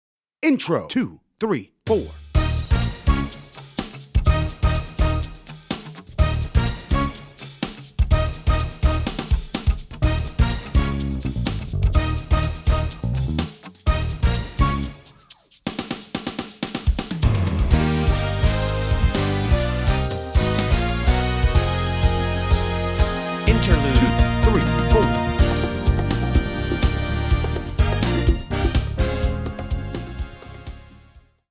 Tempo: 125bpm Key: Eb https